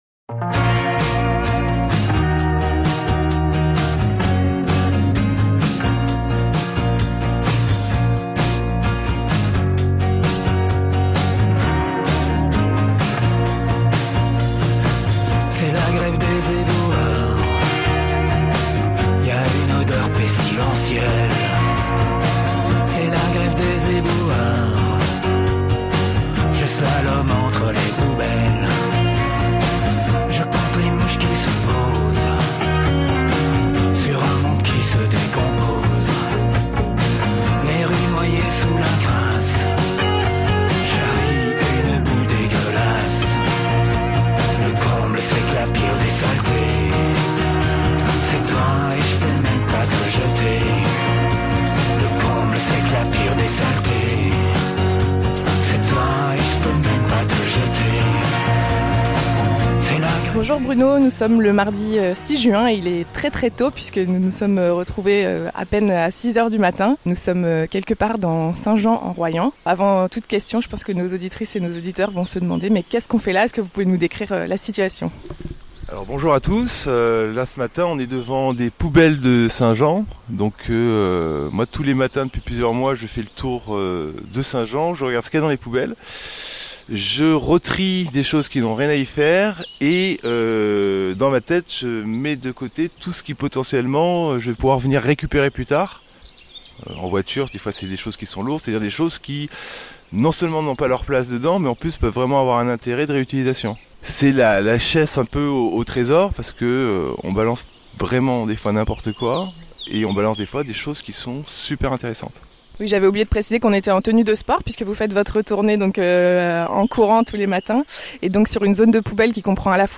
Immersion radiophonique dans les ordures du Royans ! https